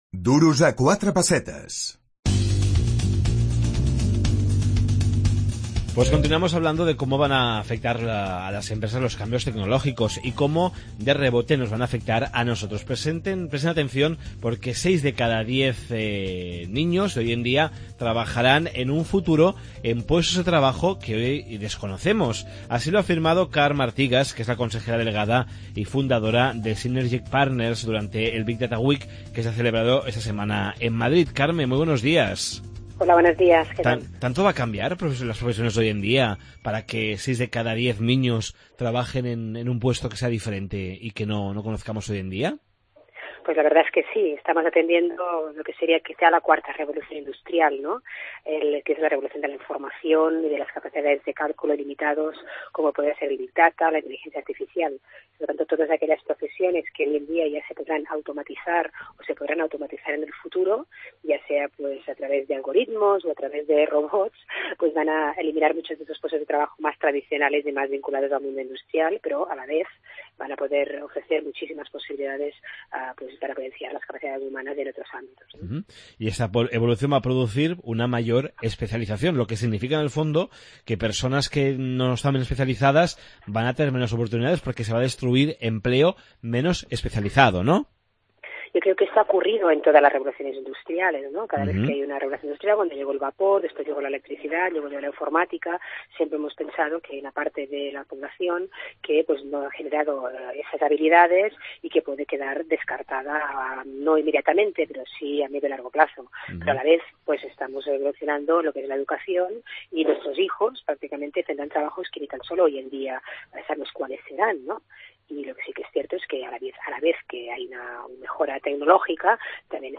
6 de cada 10 nens treballaran en un lloc de feina que avui no existeix. Entrevista Carme Artigas , Consellera Delegada i fundadora Synergic Partners